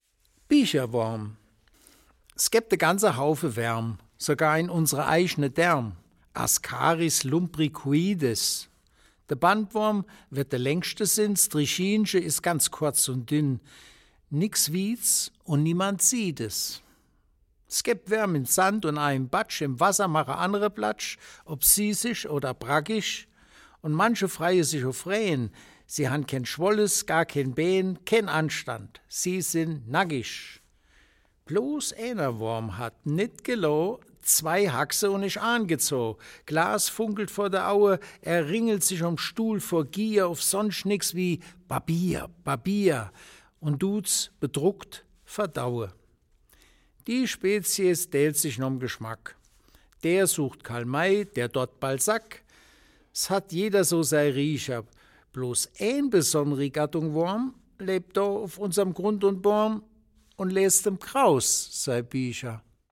Eine Auswahl an Gedichten von Heinrich Kraus, gelesen